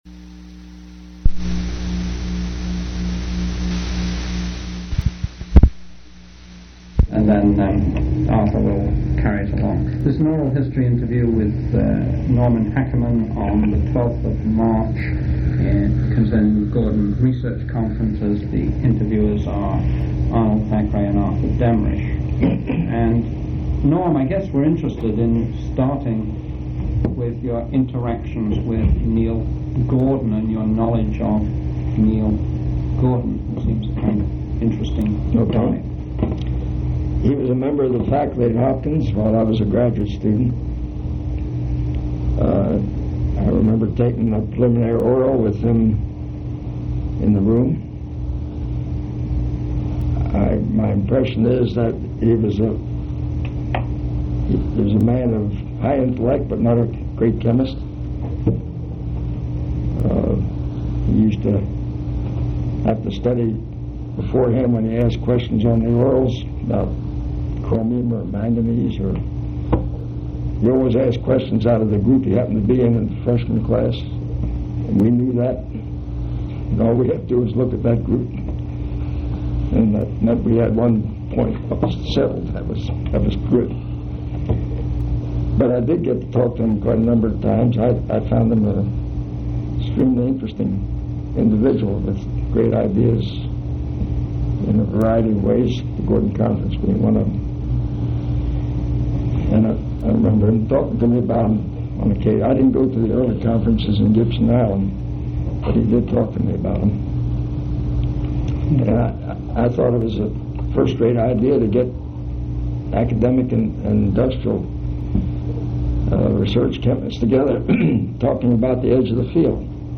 Oral history interview with Norman Hackerman
Place of interview Chemical Heritage Foundation